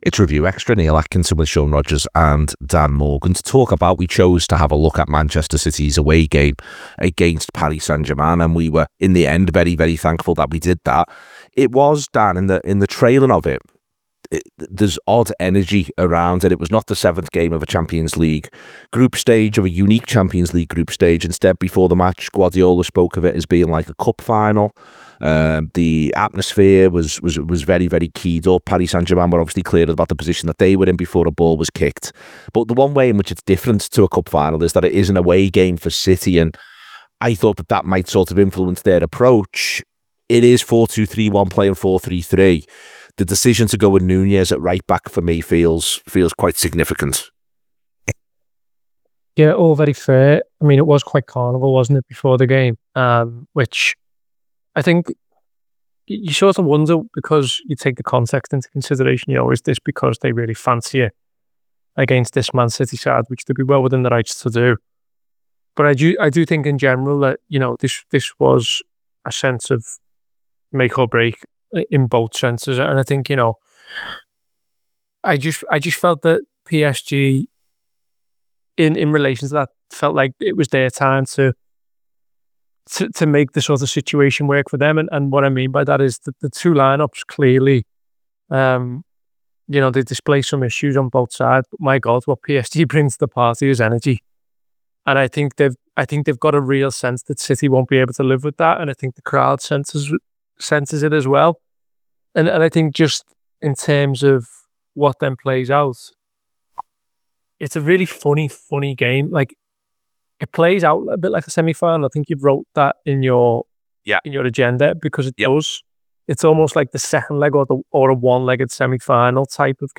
Below is a clip from the show – subscribe for more review chat around Manchester City 2 PSG 4…